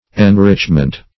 Enrichment \En*rich"ment\, n.